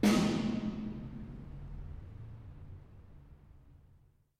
For this demonstration we will be recording the carpark of an office building. This is a very live space and offers a good example as to the power of IRs.
As a teaser though I have provided a sample of a snare drum being played into the space so you can hear what this carpark has in store for us!
snare1.mp3